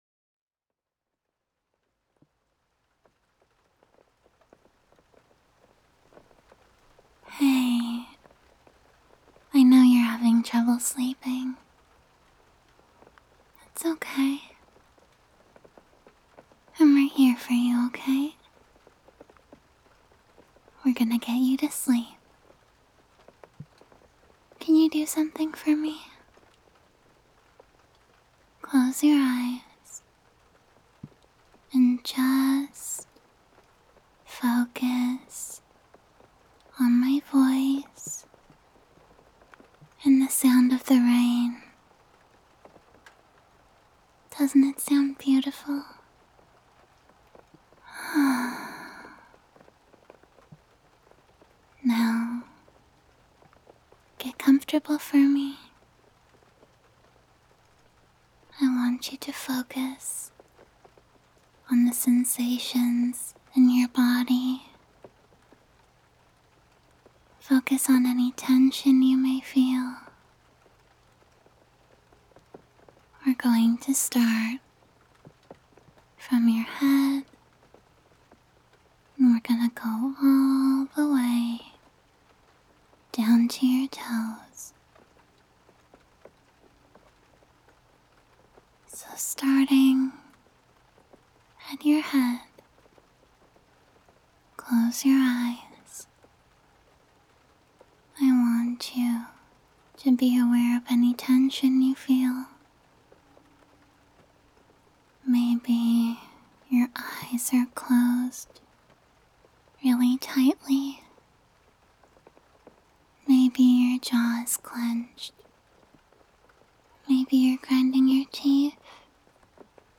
Tags: [SFW] [ASMR] [Soft Rain] [Soft City Ambience] [Improv] [Body Scan] [You're Safe] [Gentle] [Comfort] [Praise] [Softspoken] [Relaxing] [Body Inclusivity] [Sweet] [Guided and Slow] [Patient] [Whispering] [Soft Reassurance] [Stress Relief] [Sympathetic] [Slow Fadeout]